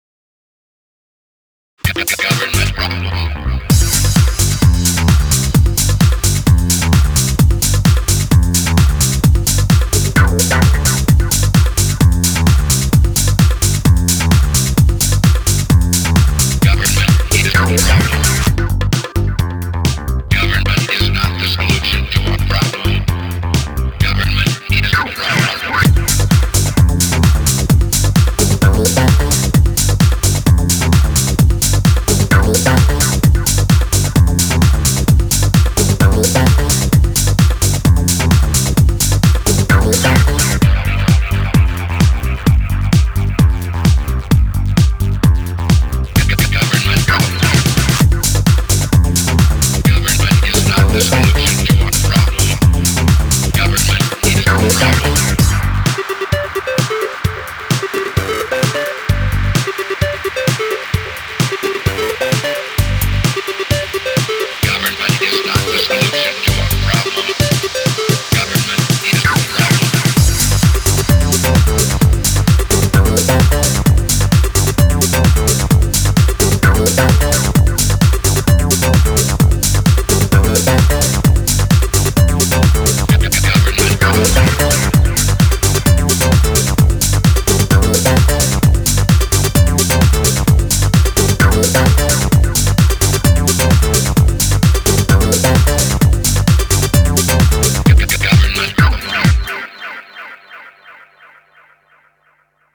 BPM129